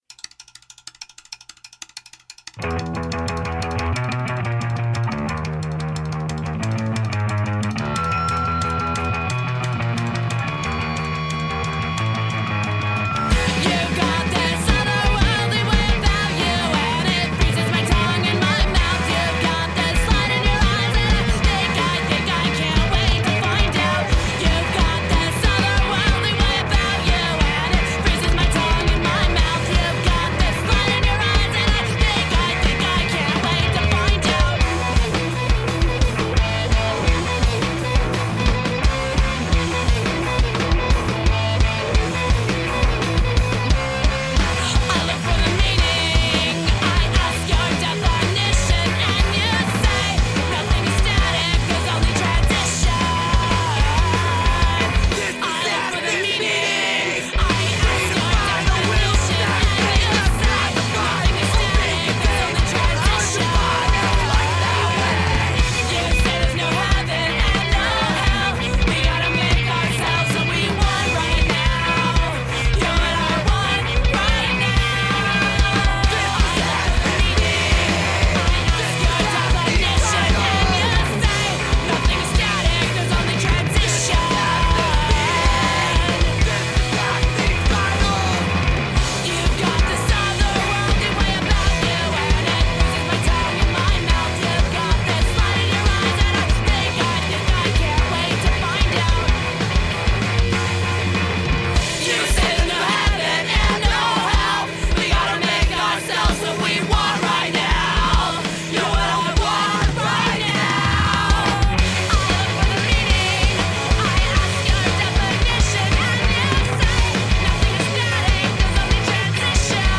punk hardcore punk riot grrrl